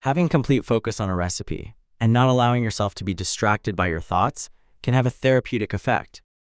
Gemini-2.0-Flash-Puck-Voice
Text-to-Speech
Synthetic